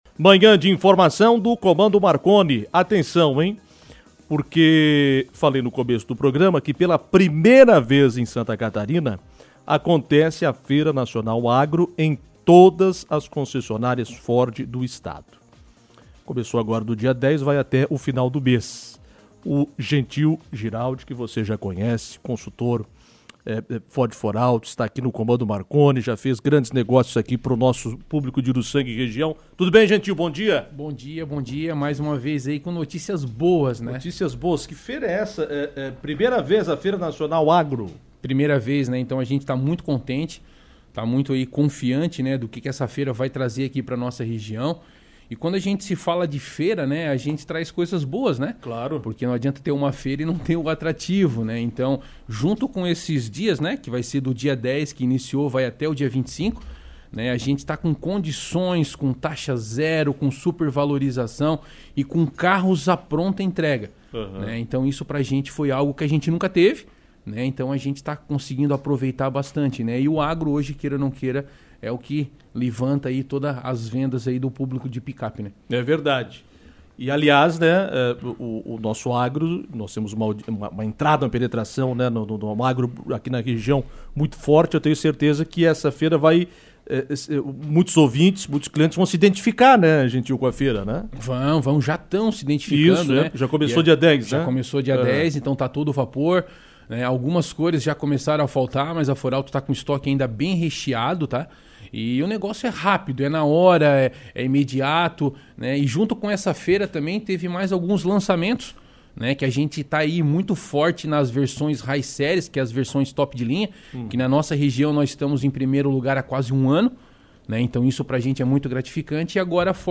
falou mais sobre as condições em entrevista